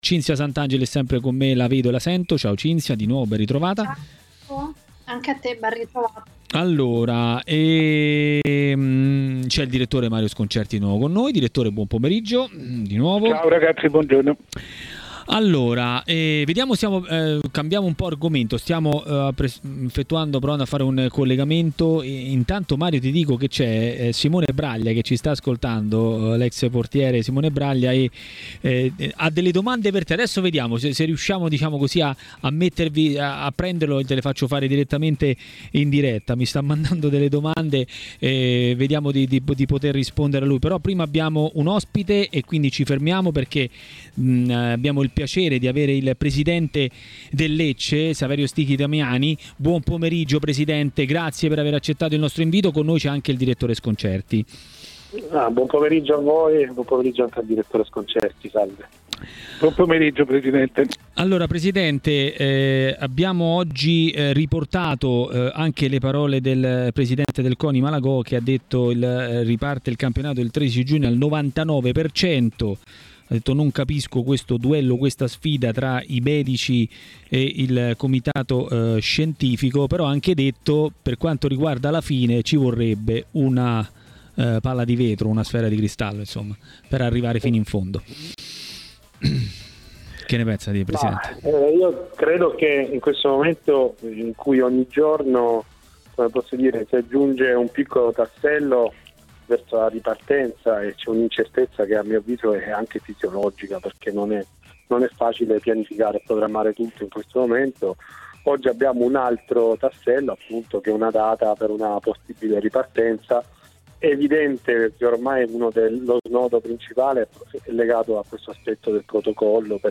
è intervenuto a Maracanà, nel pomeriggio di TMW Radio, per parlare della ripresa del campionato.